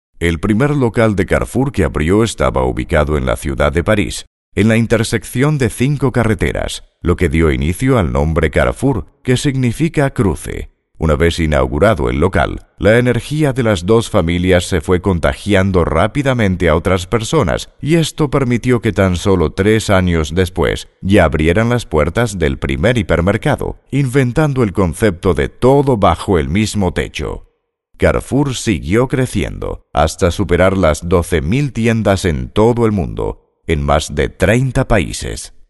Warm and deep voice for narrations, documentaries, trailers, commercials and promos.
Sprecher für castellanisch, spanisch, portugiesisch und französisch
Sprechprobe: eLearning (Muttersprache):